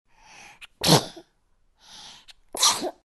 Звуки младенцев
Звук нежного чихания крохотного малыша